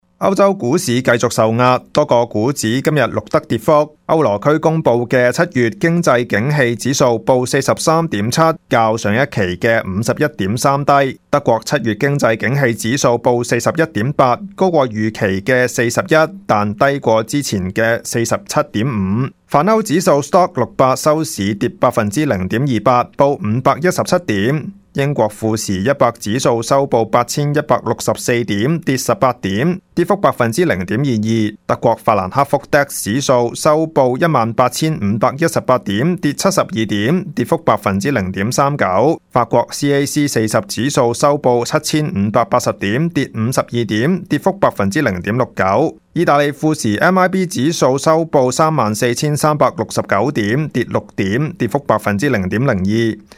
Canada/World News 全國/世界新聞
news_clip_19727.mp3